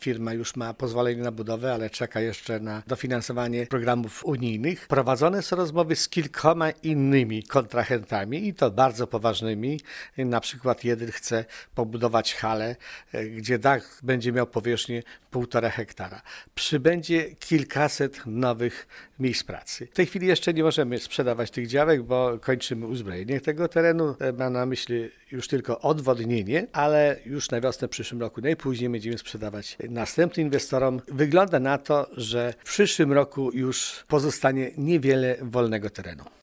Według zastępcy wójta Wiktora Osika inwestycja jest już pewna: